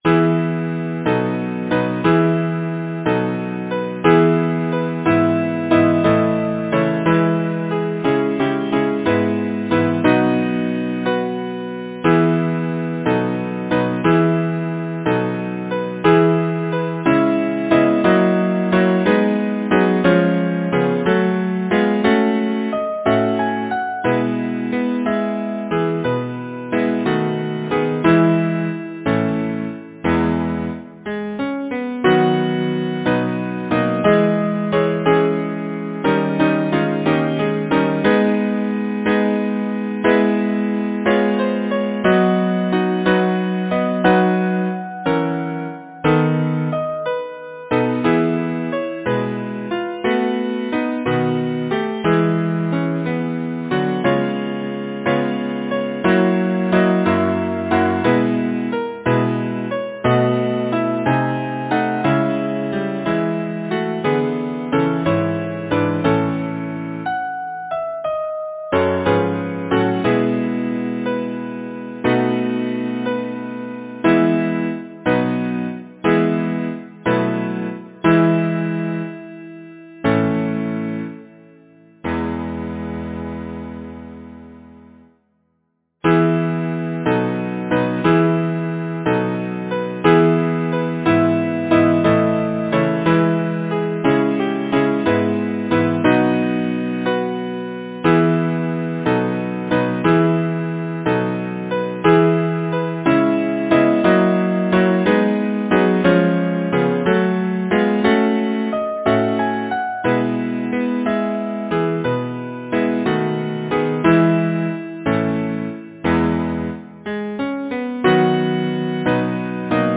Number of voices: 5vv Voicing: SATBB, with minor Alto divisi. Genre: Secular, Partsong
Language: English Instruments: A cappella